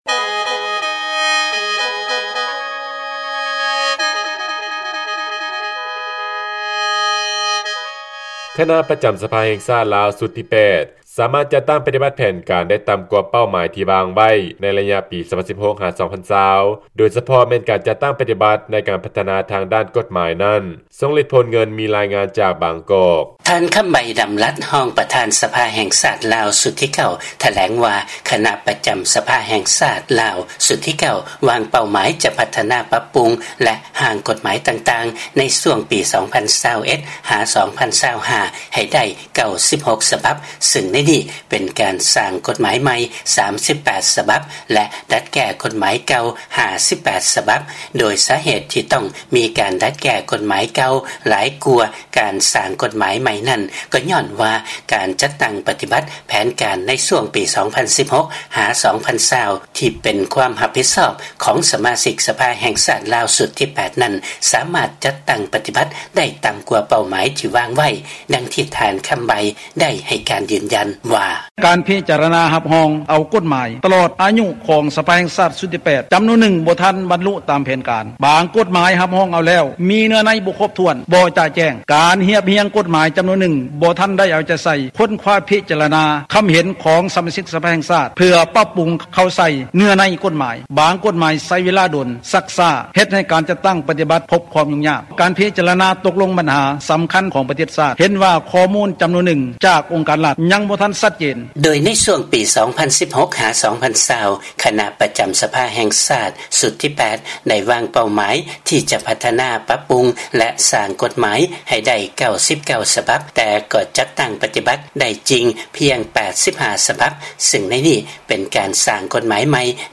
ຟັງລາຍງານ ການຈັດຕັ້ງປະຕິບັດແຜນການ ໃນຊ່ວງປີ 2016-2020 ຂອງສະມາຊິກສະພາ ຊຸດທີ 8 ສາມາດຈັດຕັ້ງປະຕິບັດໄດ້ ຕ່ຳກວ່າເປົ້າໝາຍ